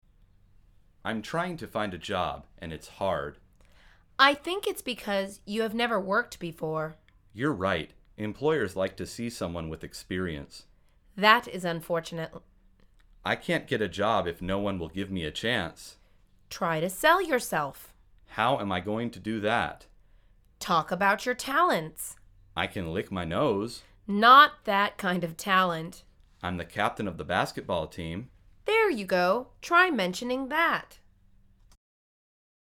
مجموعه مکالمات ساده و آسان انگلیسی – درس شماره نهم از فصل مشاغل: اولین کار